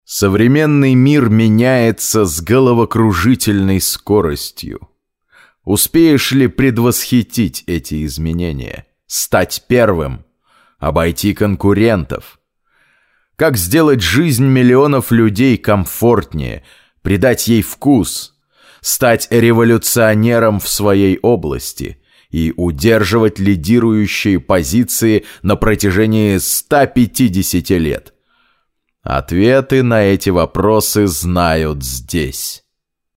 Некоторое время назад мне начали заказывать женские романы эротического содержания и я обнаружил, что мой голос имеет особенный успех у женщин :) По манере стараюсь держаться между дикторским и актёрским стилем, если нет других указаний от заказчика.
Marshall Electronics MXL 909 M-Audio Firiwire Solo